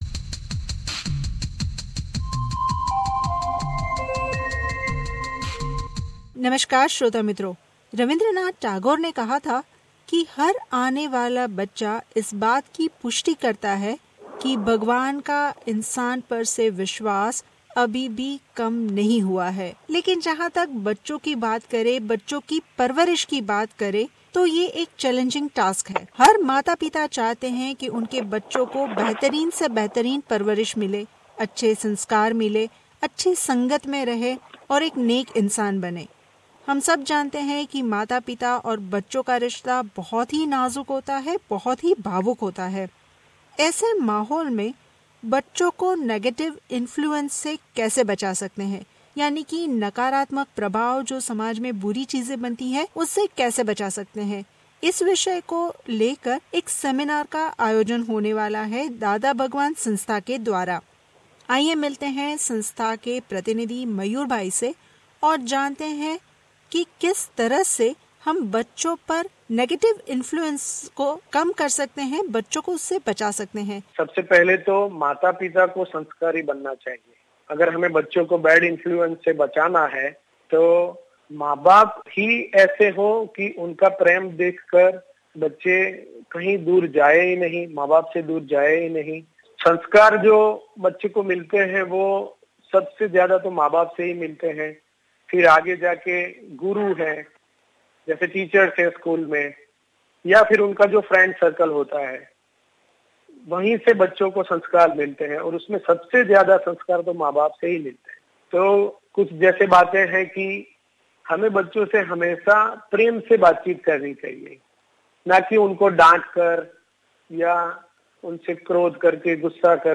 खास बातचीत